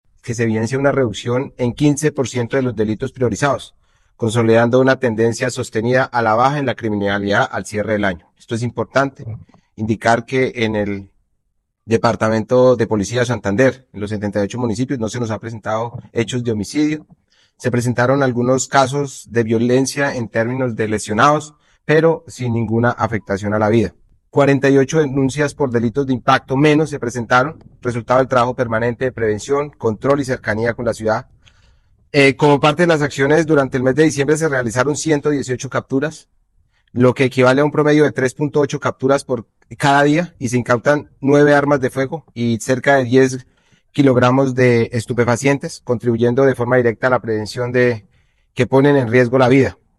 Comandante del Departamento de Policía Santander, Néstor Arévalo